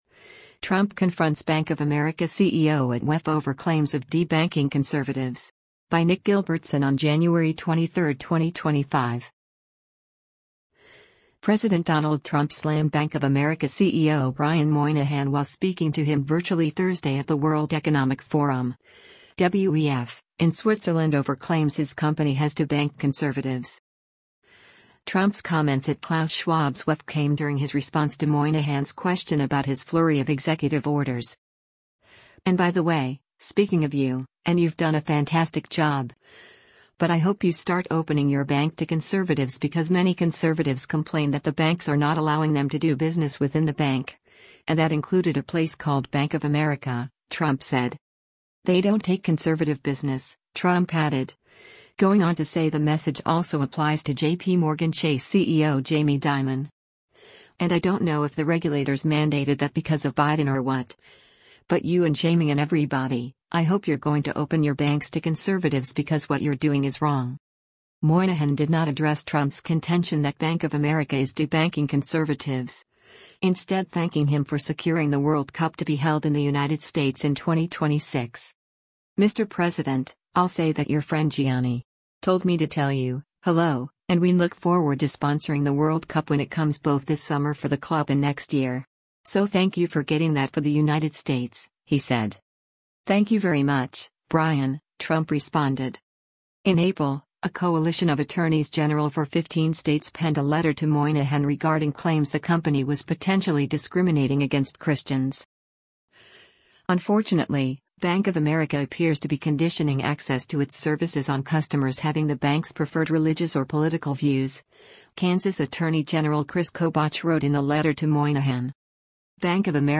President Donald Trump slammed Bank of America CEO Brian Moynihan while speaking to him virtually Thursday at the World Economic Forum (WEF) in Switzerland over claims his company has debanked conservatives.